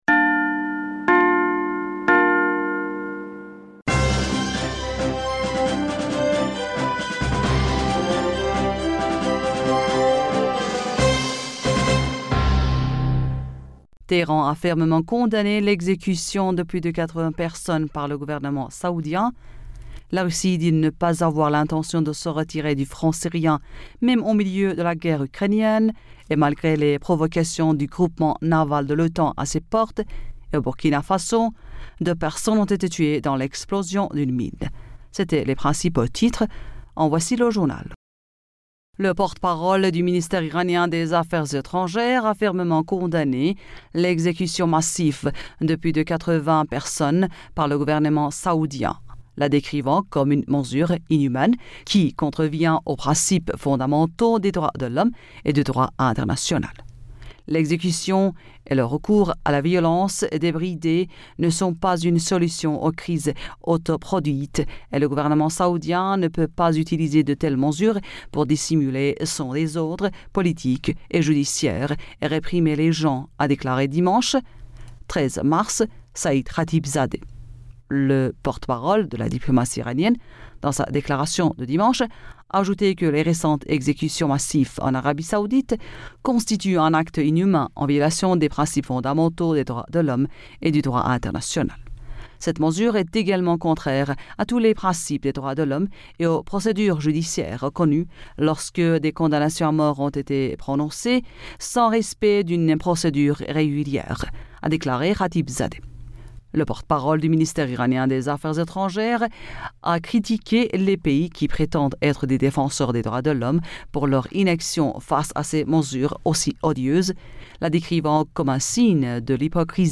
Bulletin d'information Du 14 Mars 2022